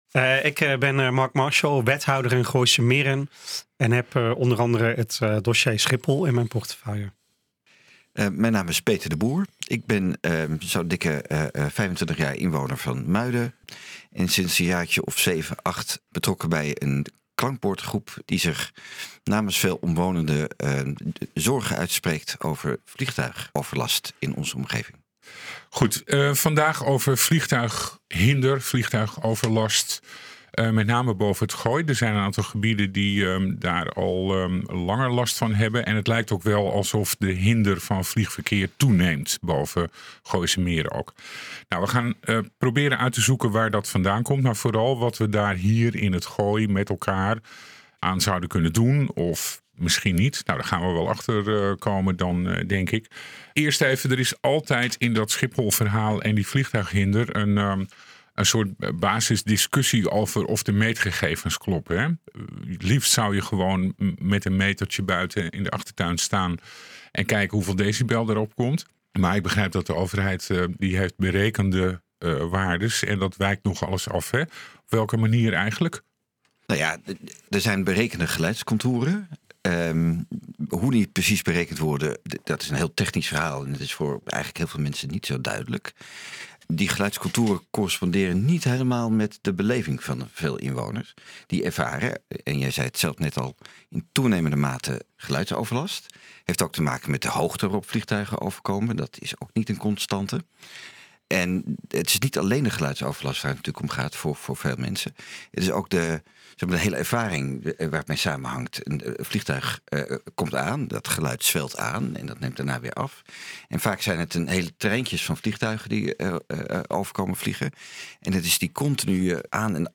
Wij praten hierover met de Klankbordgroep Minder Hinder Gooise Meren en de gemeente Gooise Meren, die samen proberen het Gooi een stem te geven.